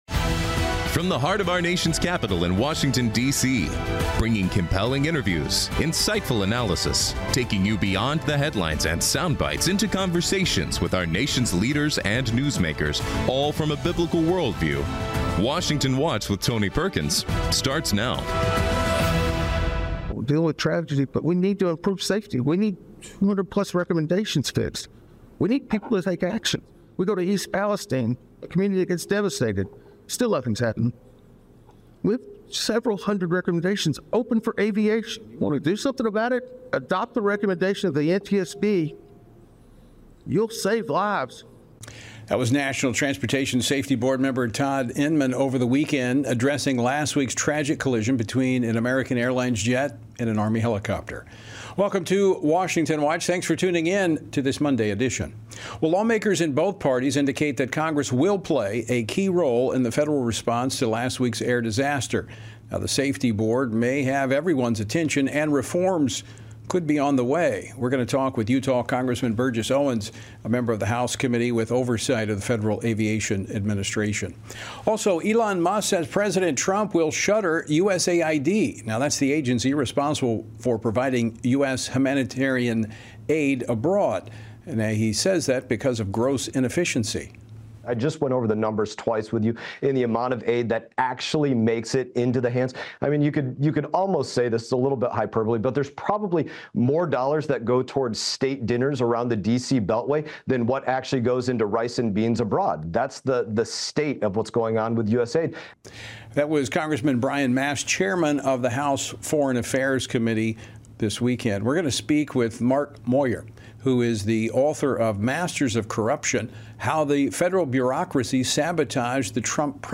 Hard hitting talk radio never has been and never will be supported by the main stream in America!
On today’s program: Burgess Owens, U.S. Representative for Utah’s 4th District, discusses the latest on the Reagan National Airport crash investigation and his Educational Choice for Children Act. Thomas Pressly, Louisiana State Senator, gives an update on the arrest warrant issued for a New York doctor indicted in Louisiana for prescribing abortion pills.
Sam Brownback, former U.S. Ambassador-at-Large for International Religious Freedom, previews the National Gathering for Prayer and Repentance and the International Religious Freedom Summit.